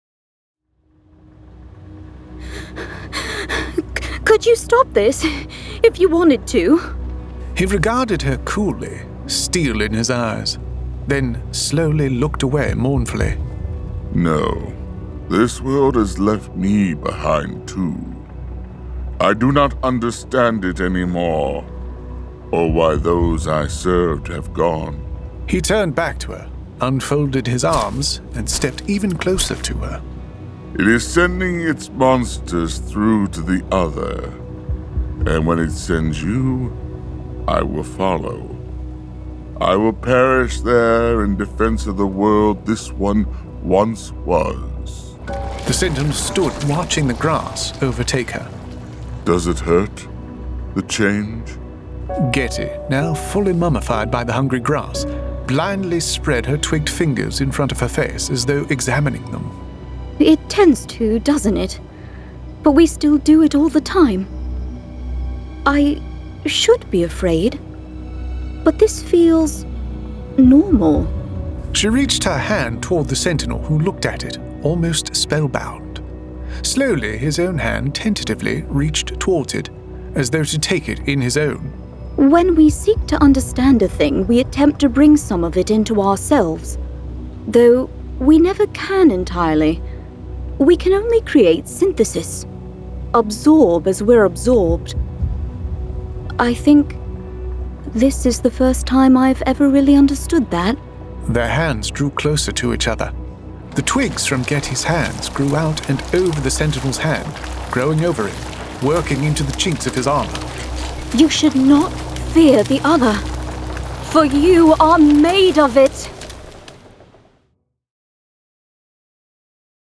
Full Cast. Cinematic Music. Sound Effects.
[Dramatized Adaptation]
Adapted directly from the graphic novel and produced with a full cast of actors, immersive sound effects and cinematic music.